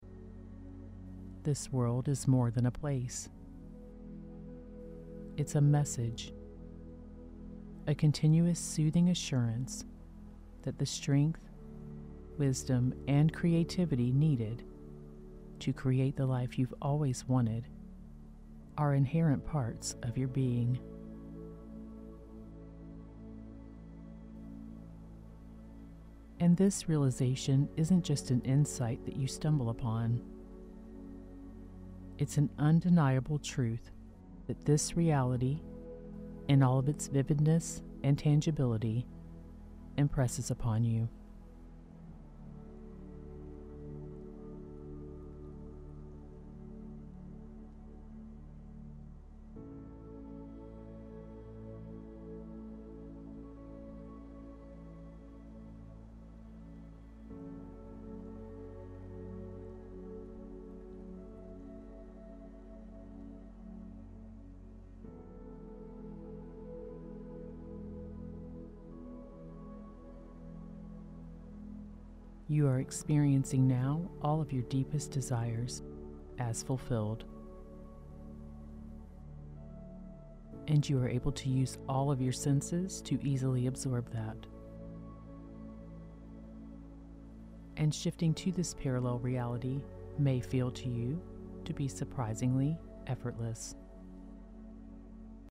Shift Reality Instantly – Guided Hypnosis (Law of Attraction Meditation) Very Powerful!
Unlock the power of your mind with this ‘Shift Reality’ Hypnosis – a powerful theta binaural track and visualization process designed for deep subconscious mind reprogramming.
Ideal for those seeking to manifest through meditation, this hypnosis session is a gateway to learning how to shift reality, offering a potent mix of guided meditation, reality shifting frequencies, visualization, and subliminals for a truly life-changing experience!
This hypnosis track is combined with a theta binaural musical track.